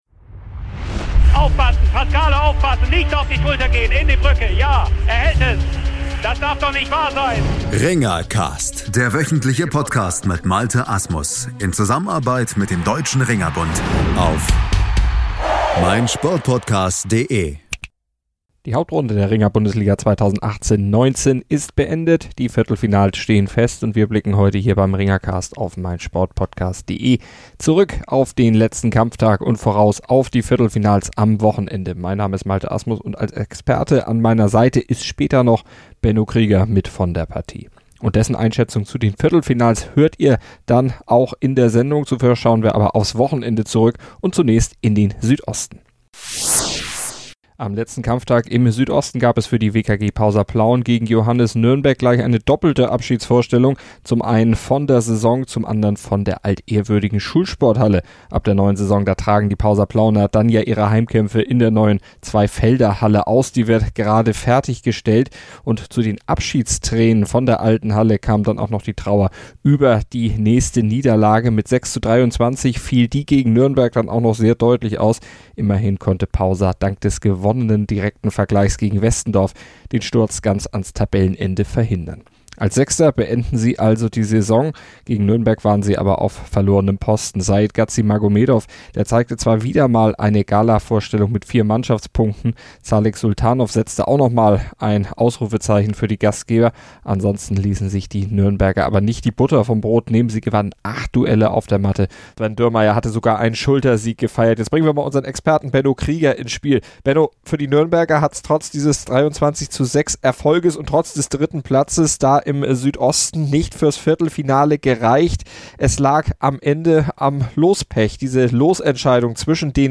Dazu wir immer viele Stimmen von den Beteiligten in der Ringer Bundesliga.